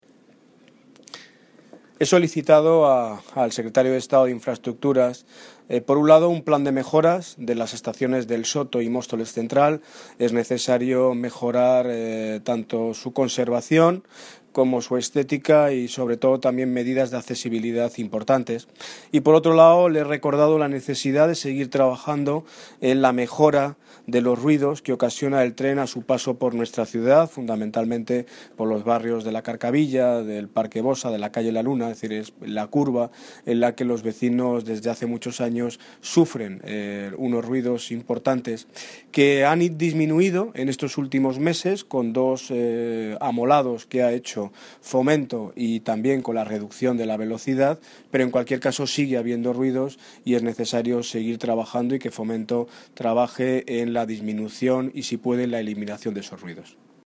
Audio - Daniel Ortiz (Alcalde de Móstoles) Sobre agilización plan de mejoras